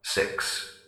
scpcb-godot/SFX/Room/Intro/PA/numbers/6.ogg at 59a9ac02fec0c26d3f2b1135b8e2b2ea652d5ff6